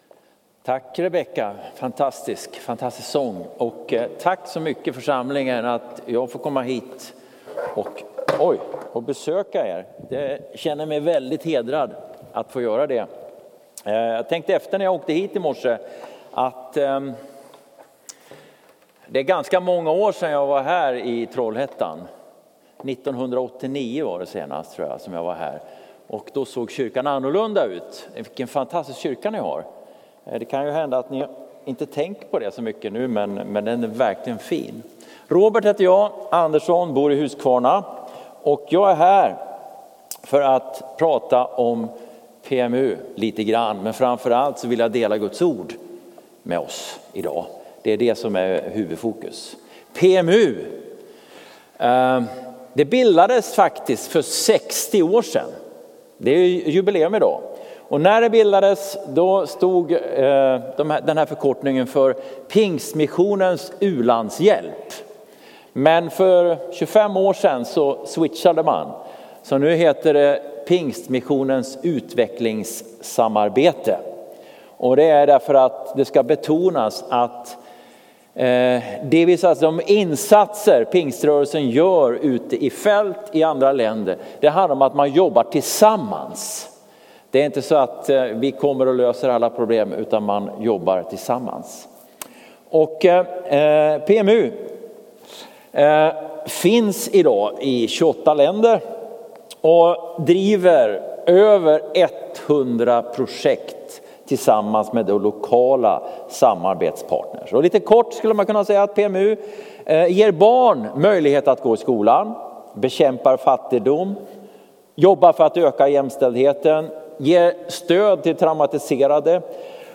1 Info om PMU och predikan 50:18